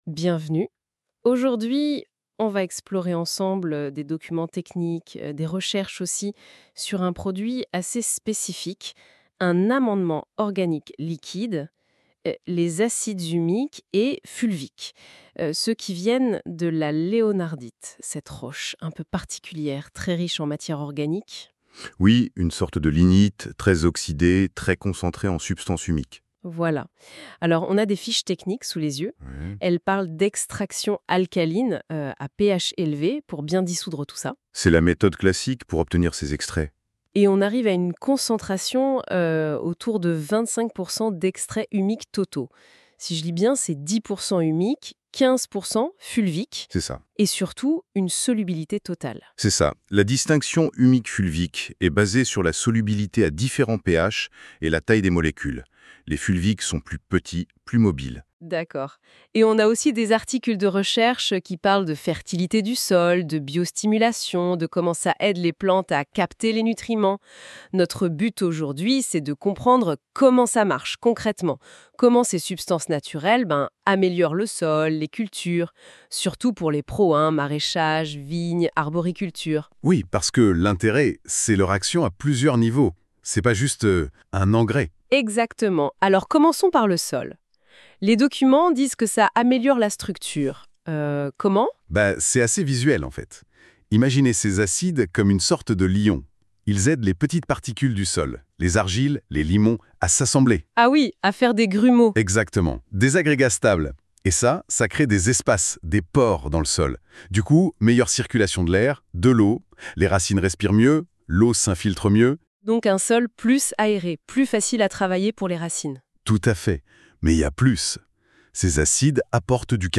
( créé par IA )